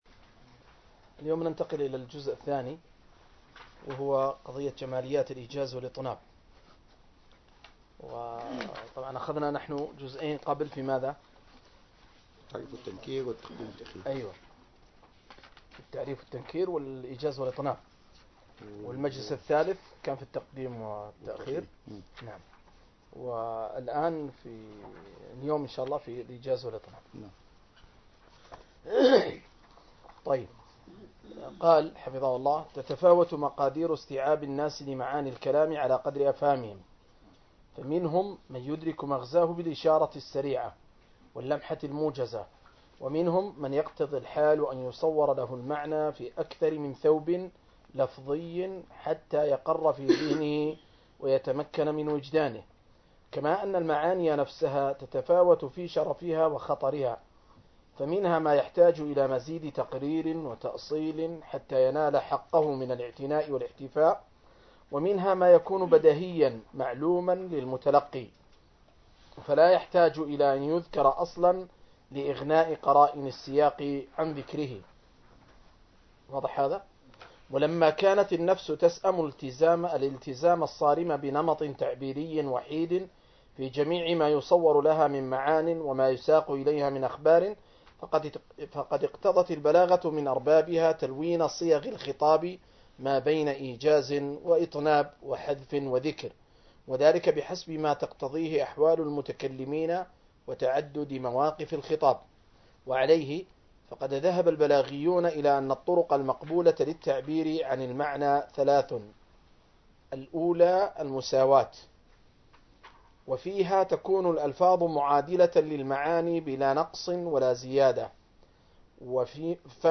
قراءة وتعليق